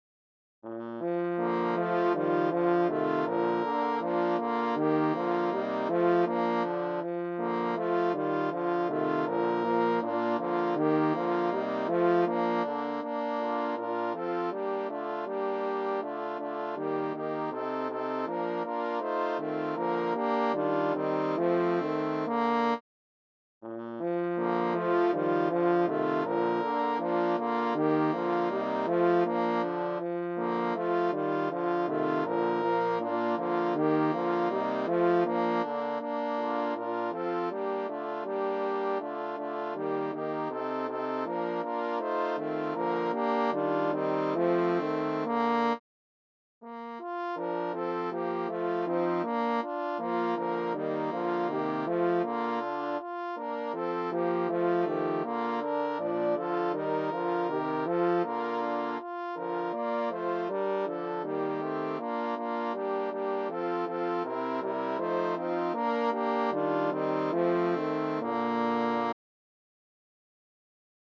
HORN QUARTET